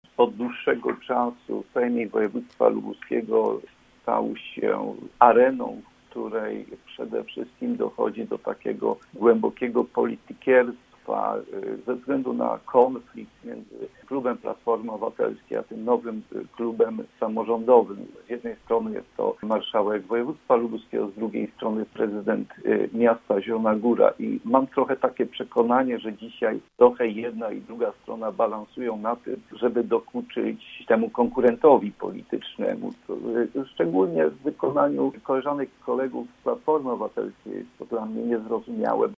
O konflikcie między klubami Zbigniew Kościk mówił dzisiaj w Rozmowie po 9: